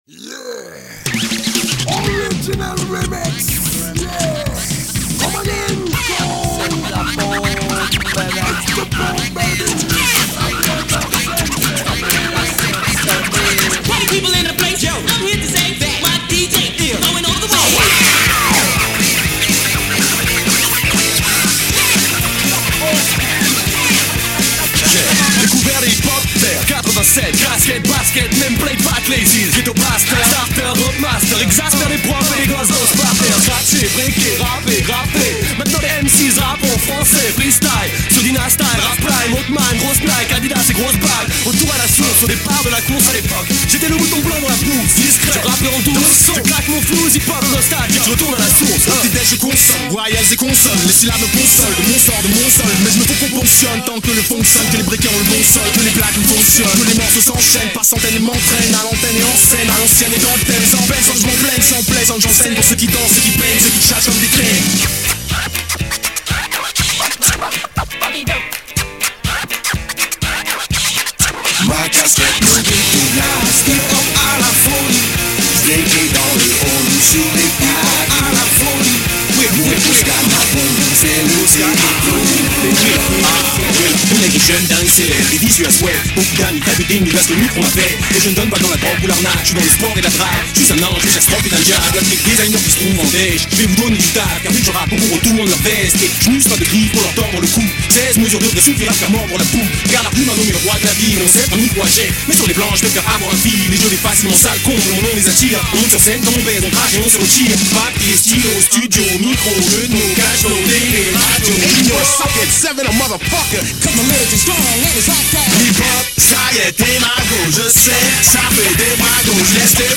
Bootleg
Acapella
Instrumentale